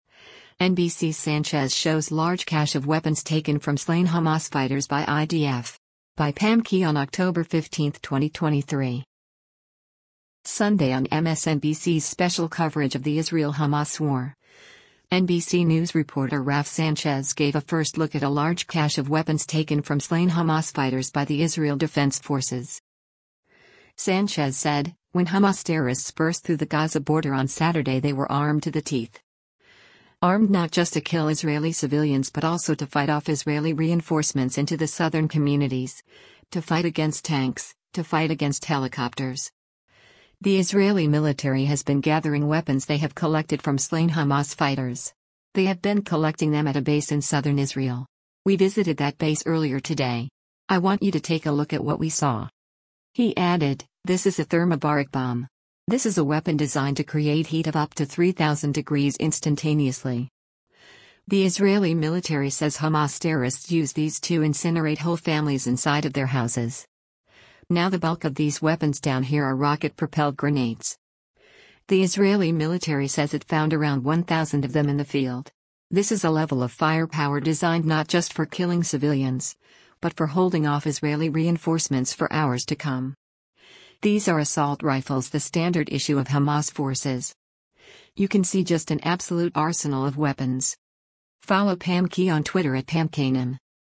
Sunday on MSNBC’s special coverage of the Israel-Hamas war